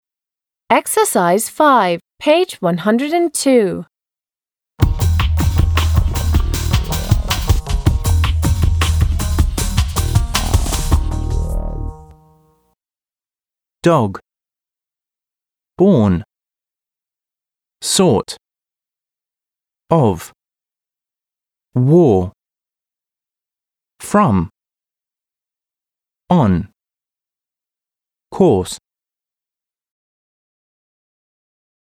/ɔ:/: − слышится, как длинное «О»
/ɒ/: − слышится, как четкая, краткая «О»